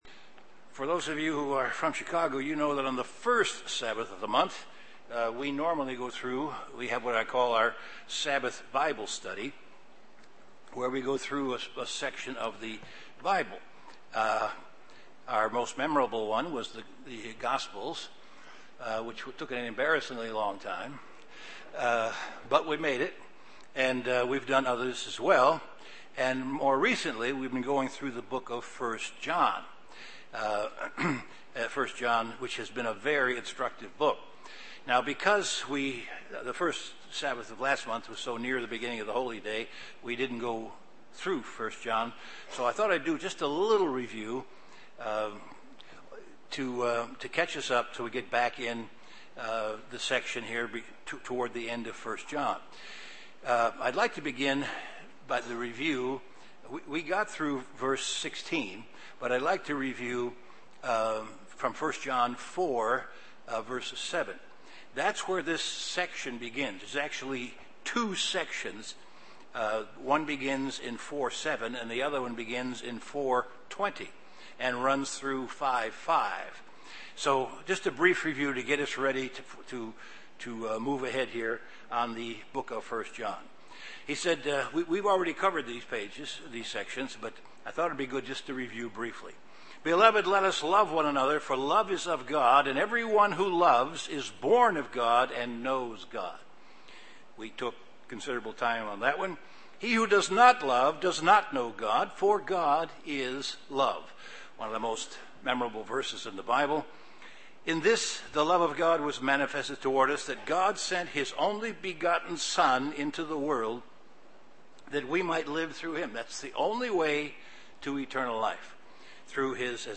Continuation of an in-depth Bible study on the book of 1 John.